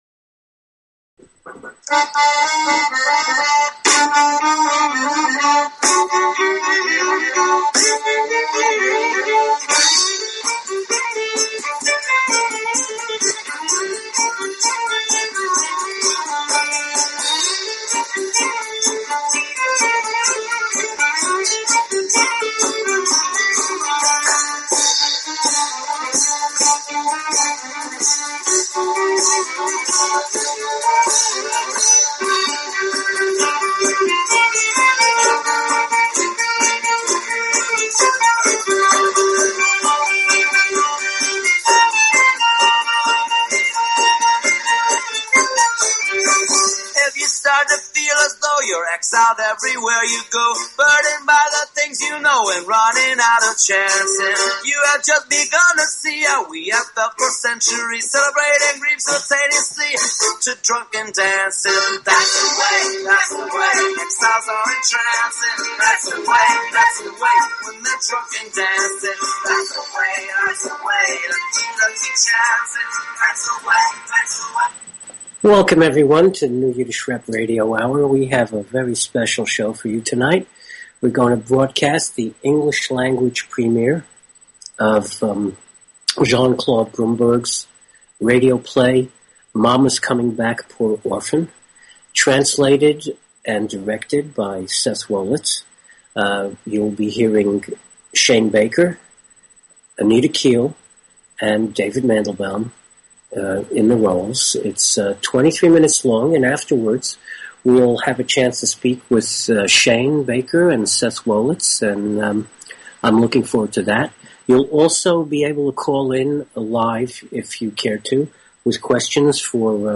Talk Show Episode, Audio Podcast, New_Yiddish_Rep_Radio_Hour and Courtesy of BBS Radio on , show guests , about , categorized as
A forum for Yiddish Culture on internet radio. Talk radio in Yiddish, in English, sometimes a mix of both, always informative and entertaining. NYR Radio hour will bring you interviews with Yiddish artists, panel discussions, radio plays, comedy, pathos, and a bulletin board of events, as well as opinions and comments from listeners.